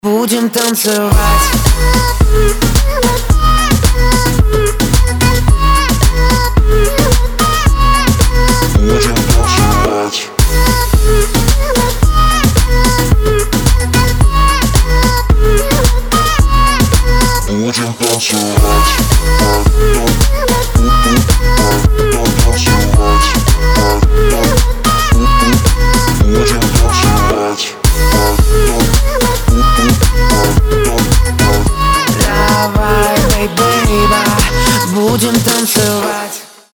• Качество: 320, Stereo
поп
мужской вокал
dance
Electronic